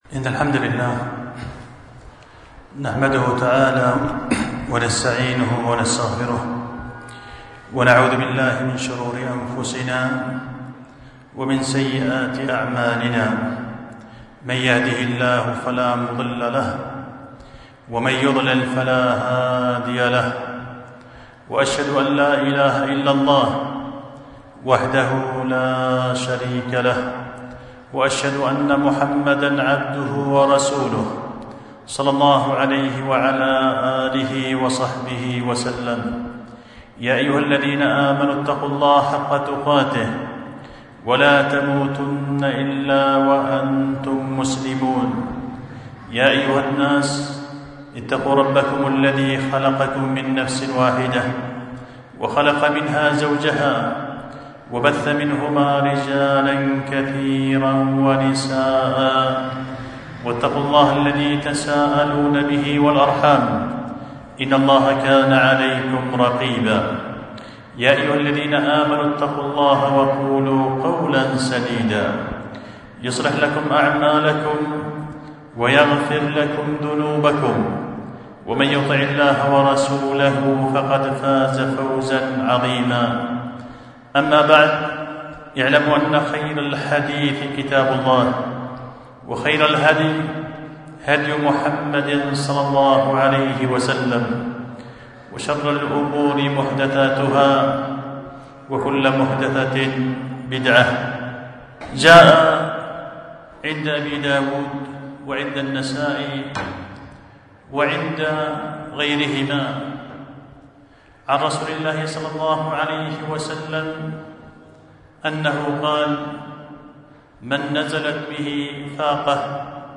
خطبة جمعة بعنوان طيب الغراس لمن أنزل حاجته بالله لا بالناس